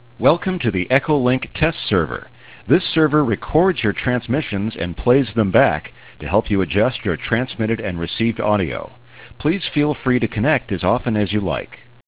. Vous pouvez contrôler votre émission et votre réception en utilisant le test, faites le 9999 sur votre clavier DTMF de votre micro et vous obtenez ce message :
ECHOTEST.wav